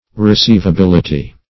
Meaning of receivability. receivability synonyms, pronunciation, spelling and more from Free Dictionary.
Search Result for " receivability" : The Collaborative International Dictionary of English v.0.48: Receivability \Re*ceiv`a*bil"i*ty\ (r[-e]*s[=e]v`[.a]*b[i^]l"[i^]*t[y^]), n. The quality of being receivable; receivableness.